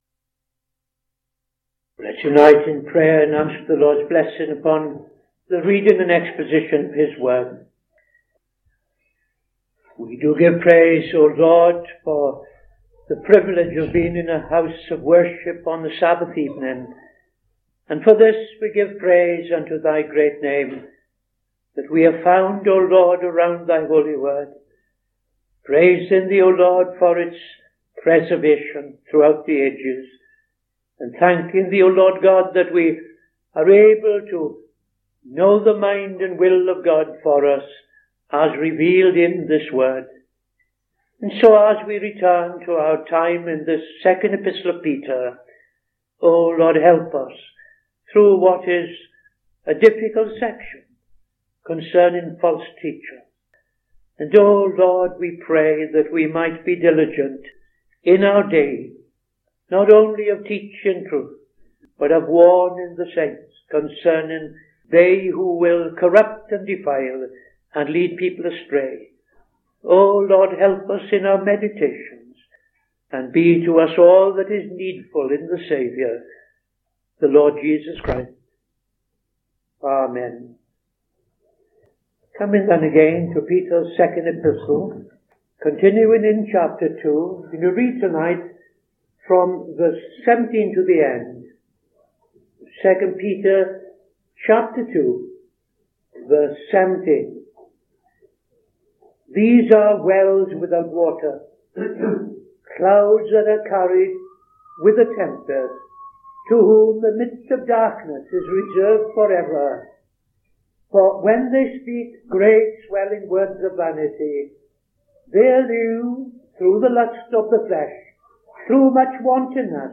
Saturday Sermon - TFCChurch
Opening Prayer and Reading II Peter 2:17-22